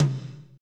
TOM F S H0ZL.wav